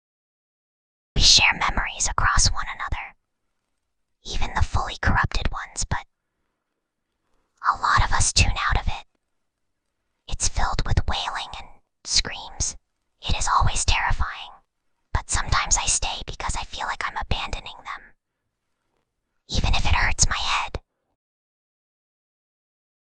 File:Whispering Girl 29.mp3
Whispering_Girl_29.mp3